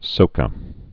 (sōkə)